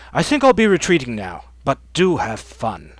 vs_fScarabx_flee.wav